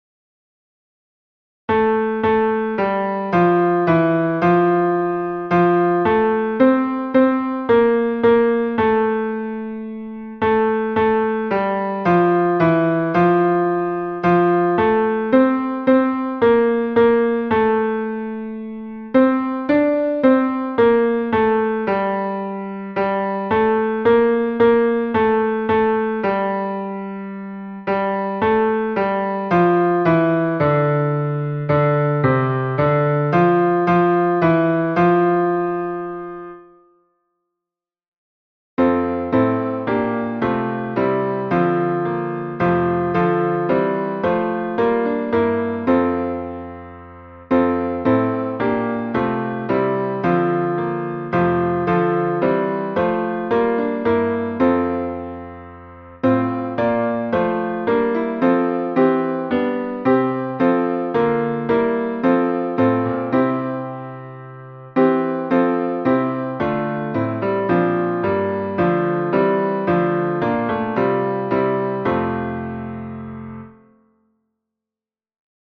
MP3 version piano
Chant d effleure tenor (piano)
chant-d-effleure-tenor.mp3